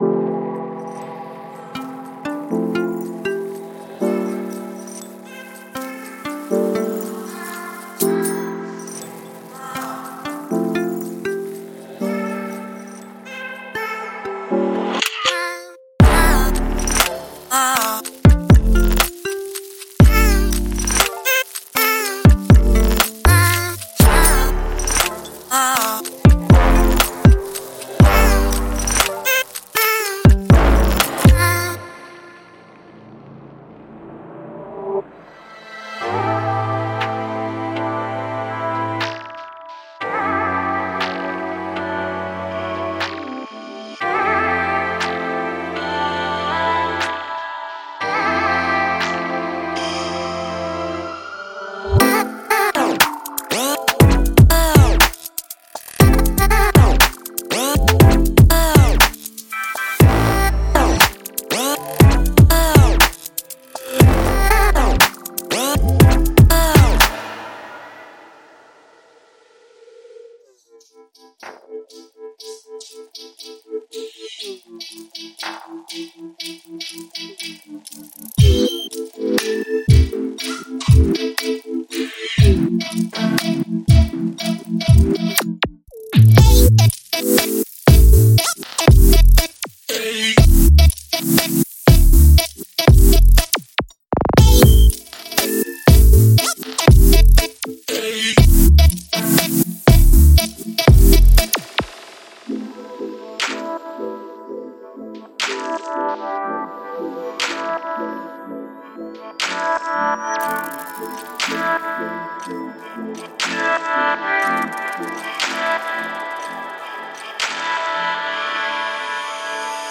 酸和房屋样品包
• 77鼓一击，包括小军鼓，敲击，踢，踩hat和拍手（19 Mb）
陷阱打击乐鼓
Lofi鼓组–基本音色
陷阱样本包和嘻哈鼓套件–基本声音
罗兹钢琴样品–基本音色
吉他样本和原声和弦–基本音色